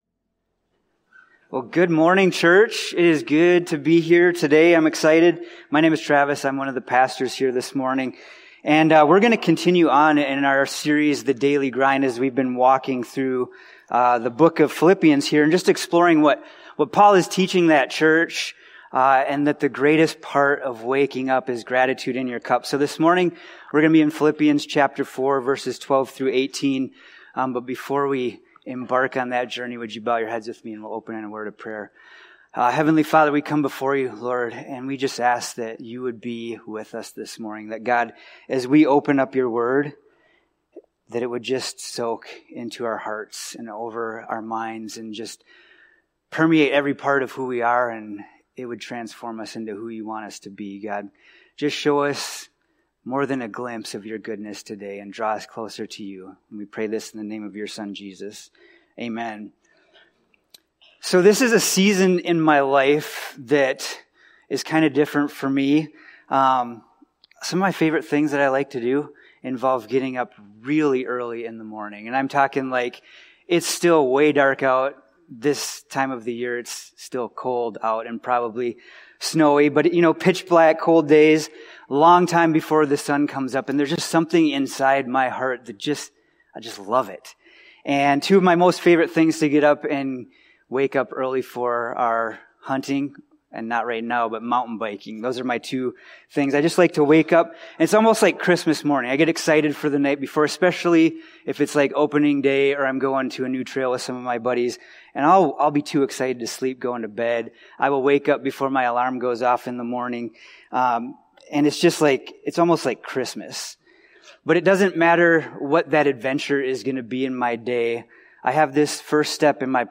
A message from the series "Daily Grind."
This podcast episode is a Sunday message from Evangel Community Church, Houghton, Michigan, April 6, 2025.